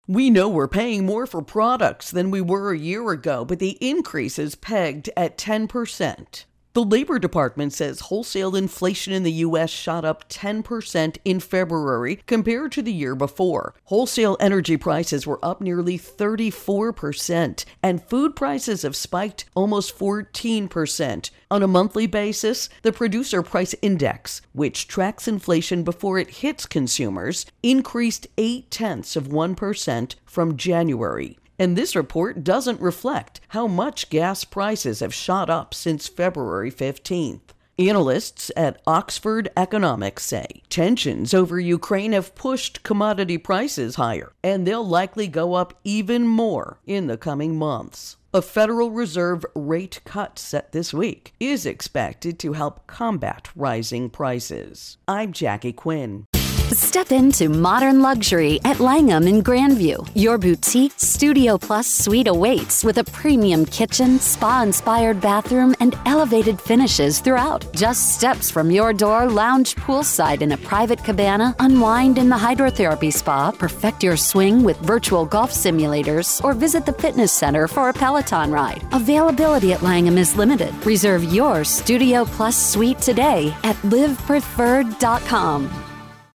Producer Prices Intro and Voicer